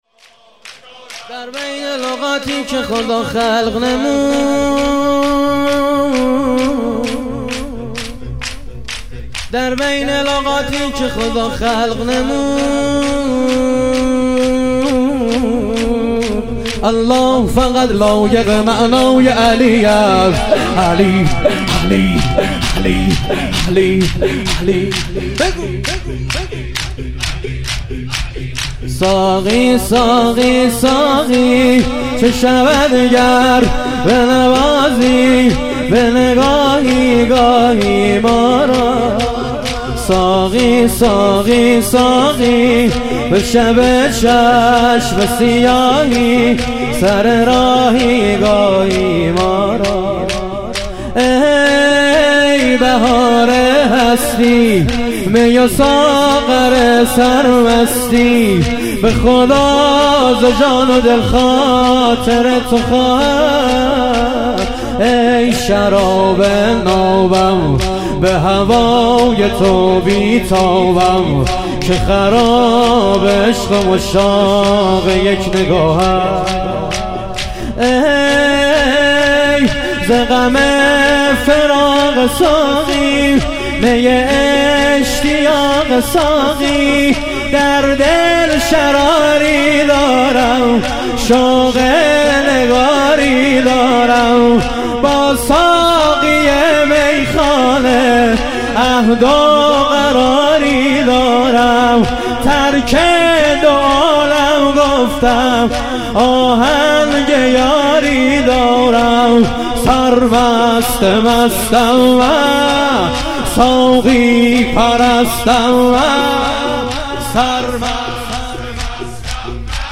ظهور وجود مقدس امام حسن عسکری علیه السلام - شور